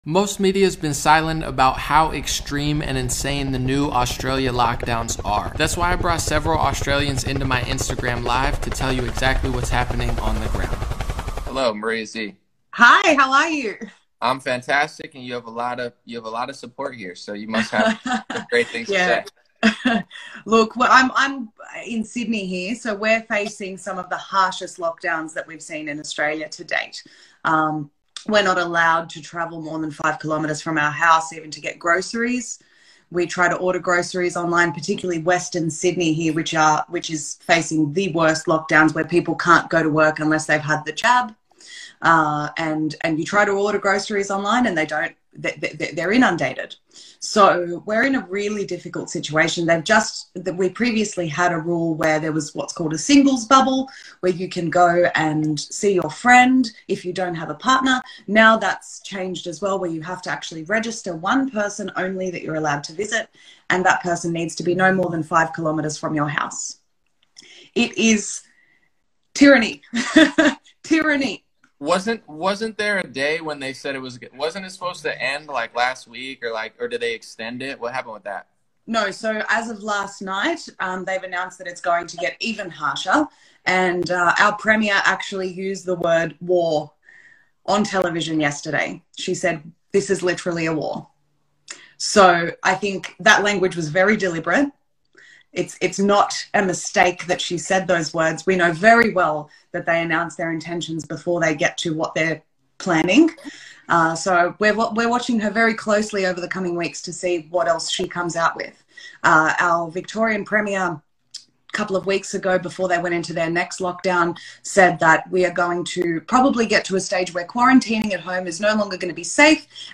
Australias New Lockdowns Are REAL & INSANE - Listen To These 5 Testimonials Of Aussies. 15 aug. 2021